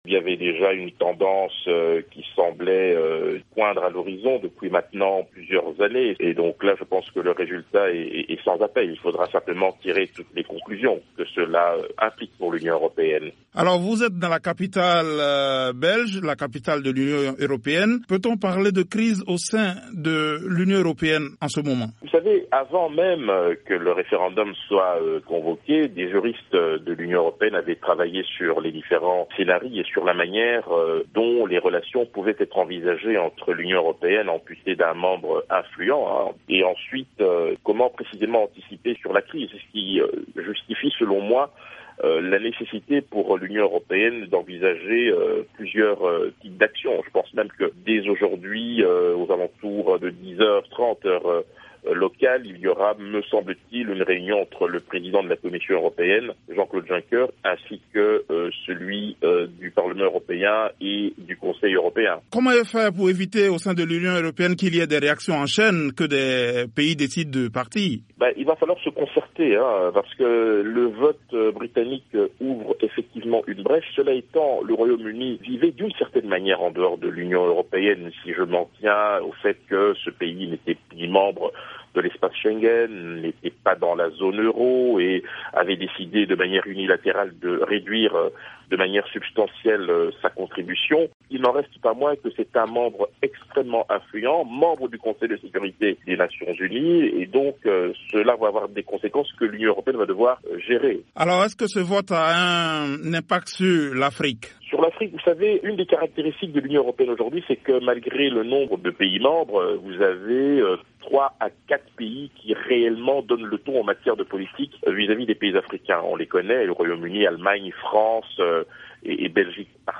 joint à Bruxelles